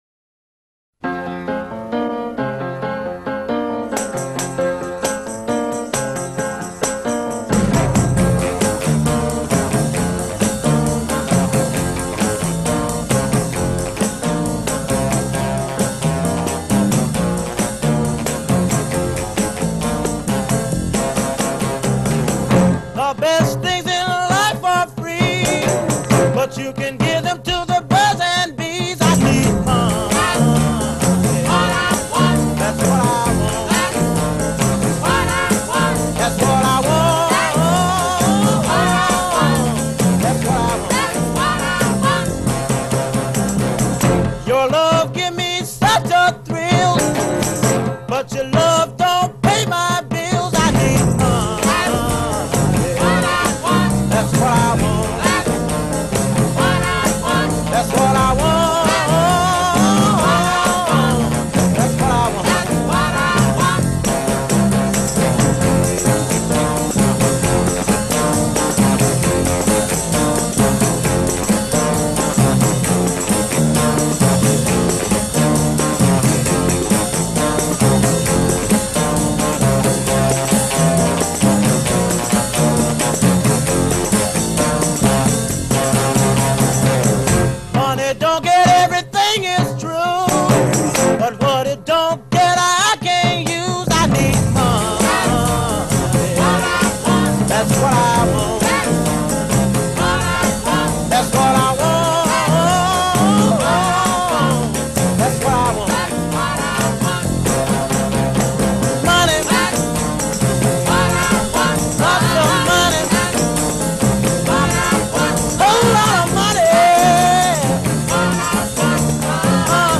Nuestra agrupación toca las canciones de los grandes